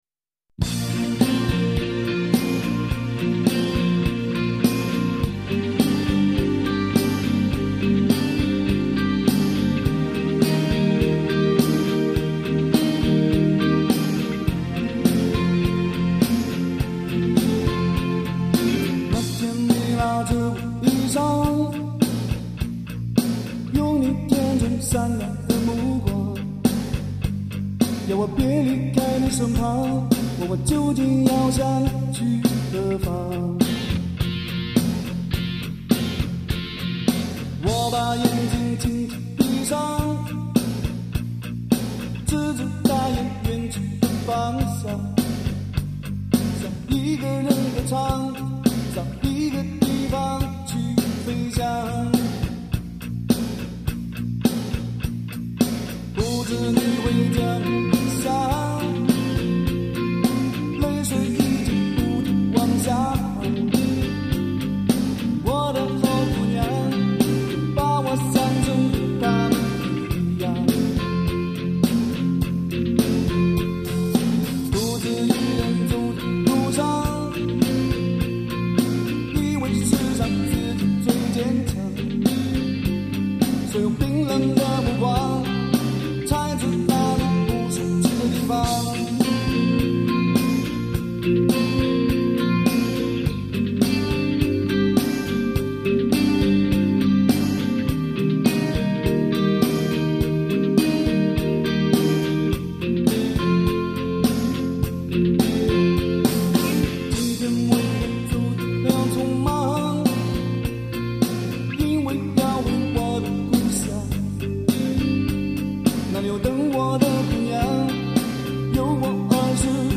金典的摇滚，耳目一新的说唱，醉人的旋律，让你无法释怀的全新音乐享受。